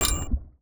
UIClick_Menu Water Splash Metal Hit 01.wav